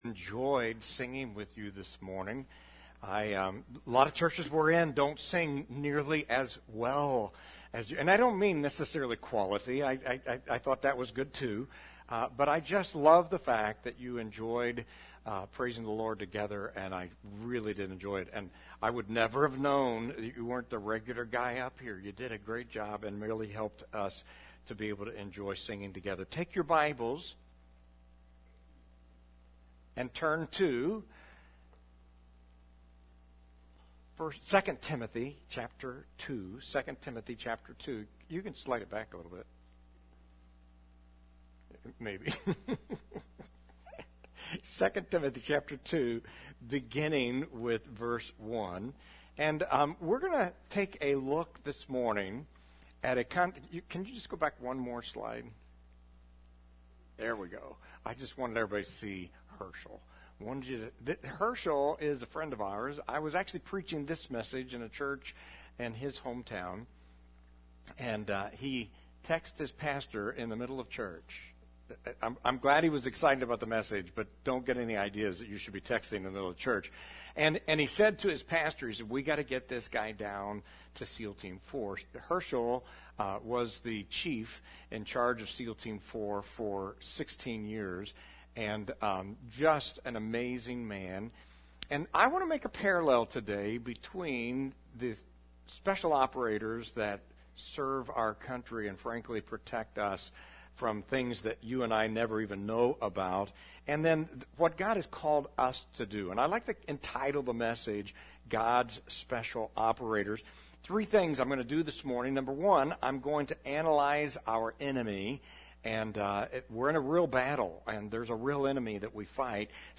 The-Exchange-Sunday-Service.mp3